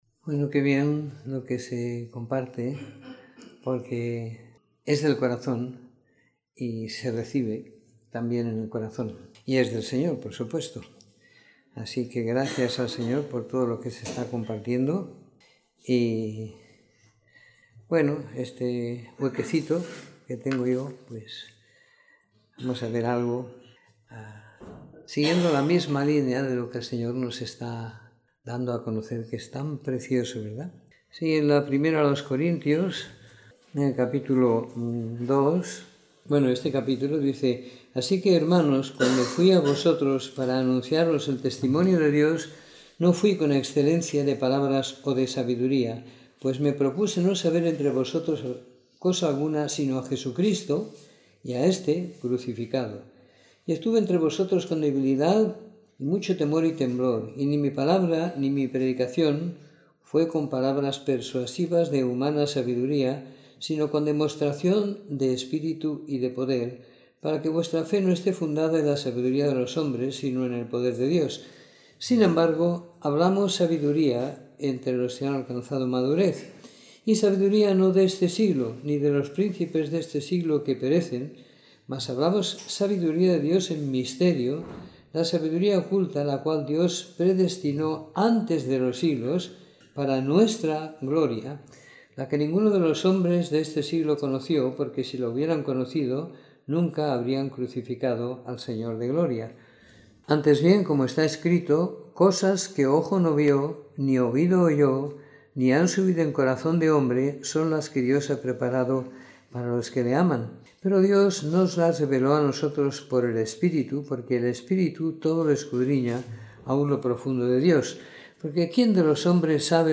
Domingo por la Tarde . 07 de Enero de 2018